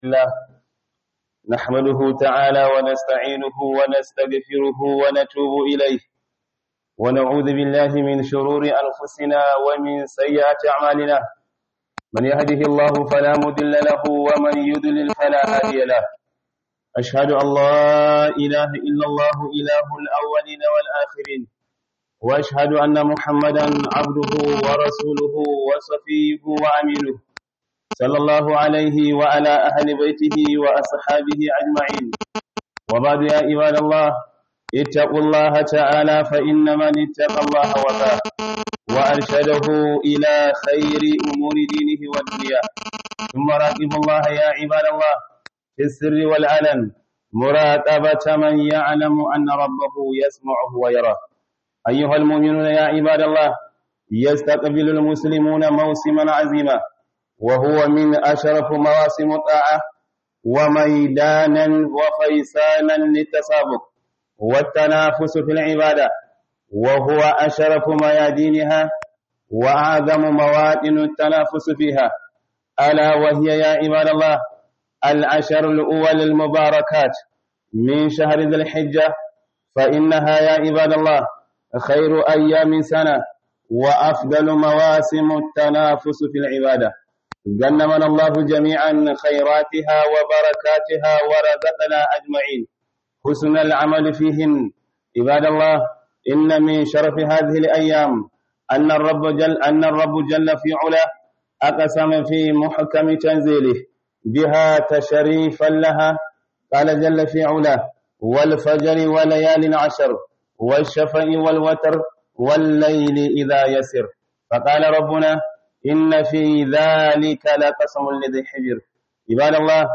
Khudubar Jibwis Low-cost Ningi - Kwanaki goman farko na Zulhijja